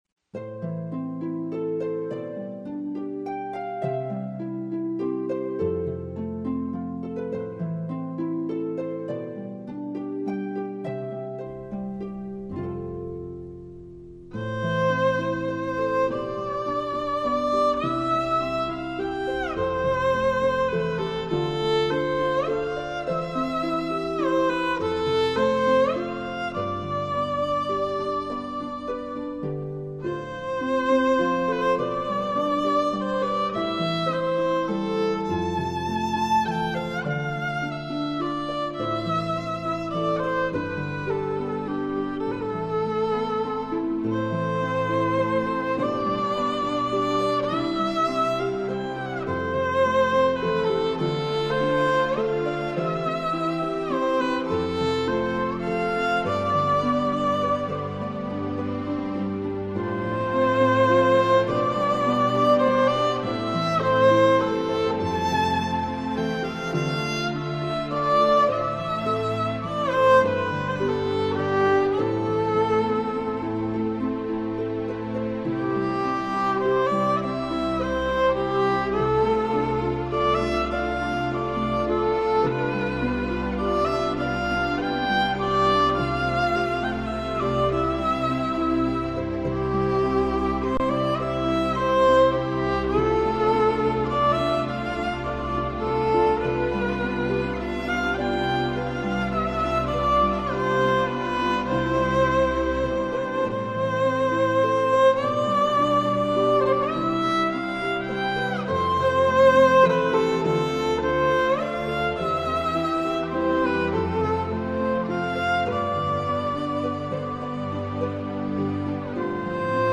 música ambiental violines y arpa294 - copia - copia.mp3